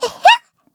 Taily-Vox_Happy1_kr.wav